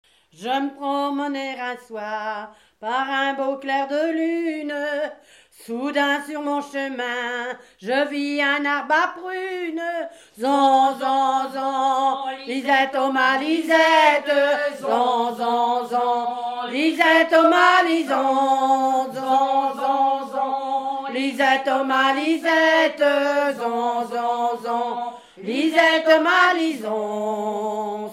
Genre laisse
chansons traditionnelles
Pièce musicale inédite